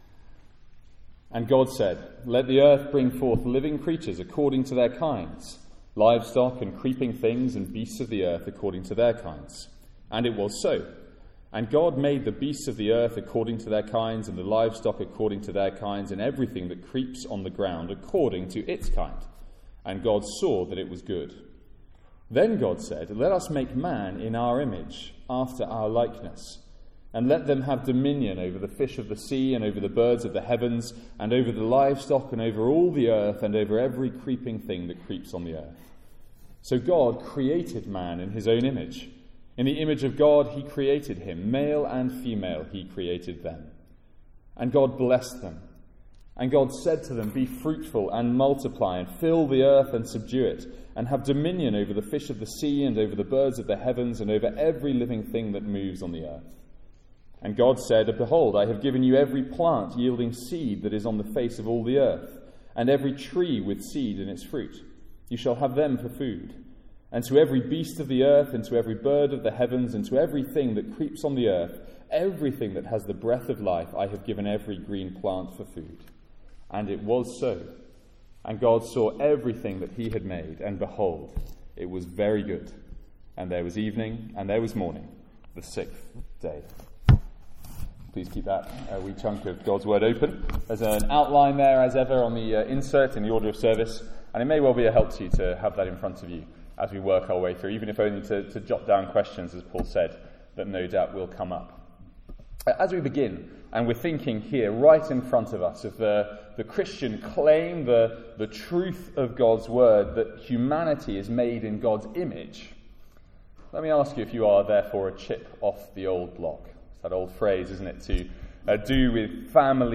Sermons | St Andrews Free Church
From our evening series in Genesis.